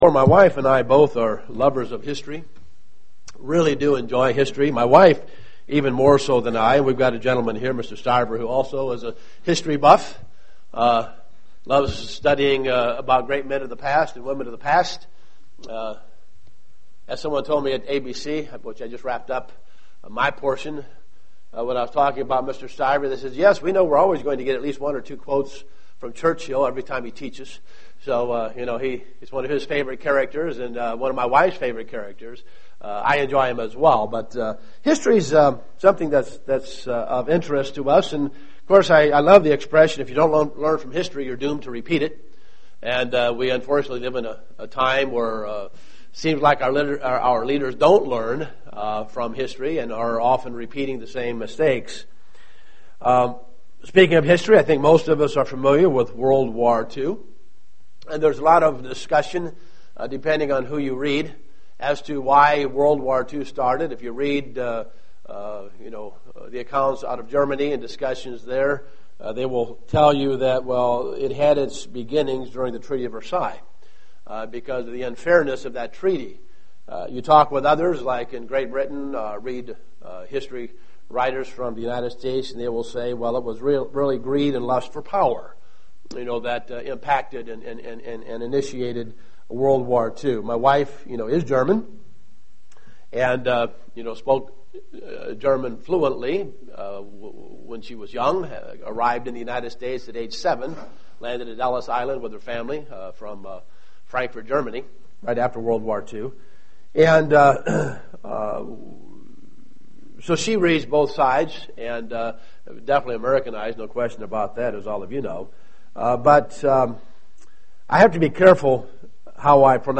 Given in Dayton, OH
UCG Sermon Studying the bible?